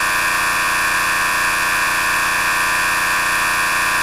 rainbow_laser.ogg